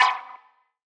DDW Perc 1.wav